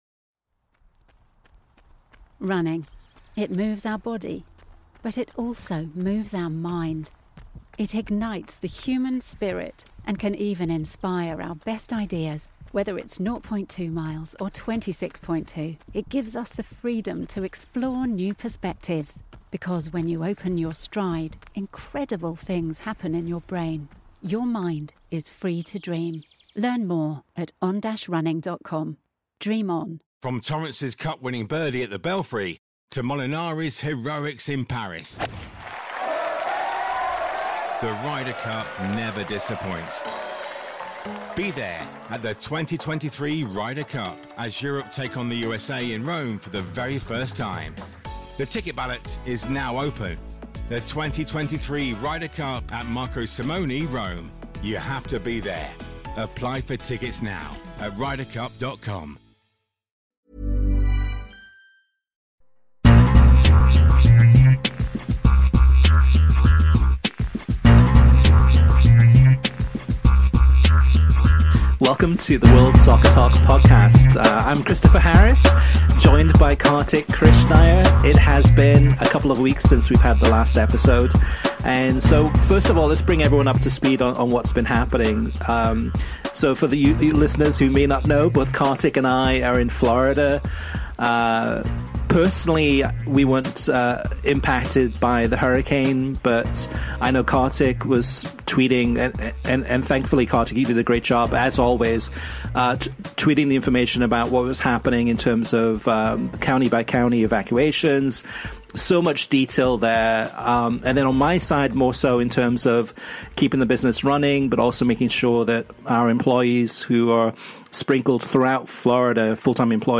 Plus we have Listener Mailbag with your questions answered on-air.